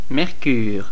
Ääntäminen
Synonyymit vif-argent hydrargyre Ääntäminen Tuntematon aksentti: IPA: /mɛʁ.kyʁ/ Haettu sana löytyi näillä lähdekielillä: ranska Käännös Substantiivit 1. живак {m} Suku: m .